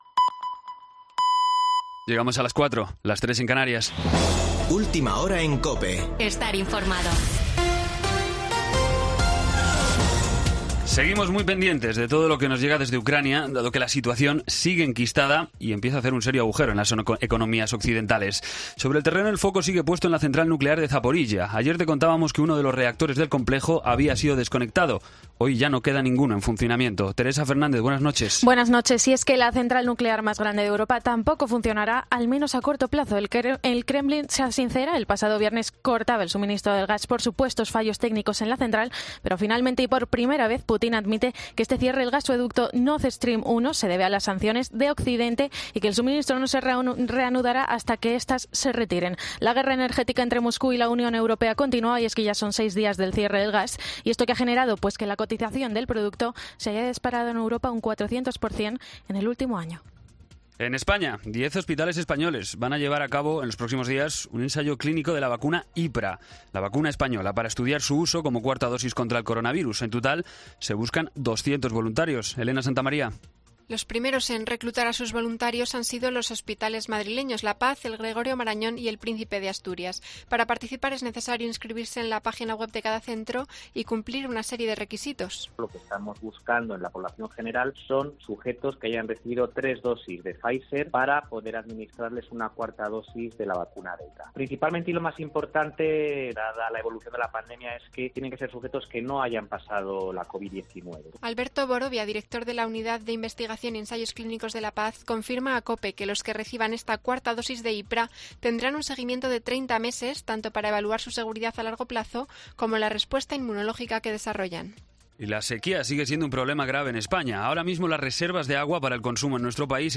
Boletín de noticias COPE del 6 de septiembre a las 04:00 horas